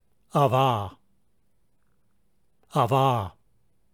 Transliteration from Greek to English letters: abba